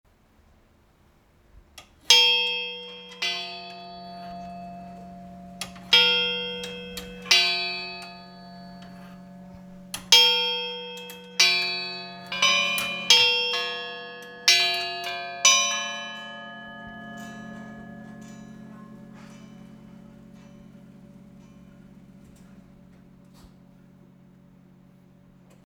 今回の訪問では，実際に筆者らも音と戯れる体験をし，さらにミュージアムの音を収録させていただきました。
♪「おとぎチャイム」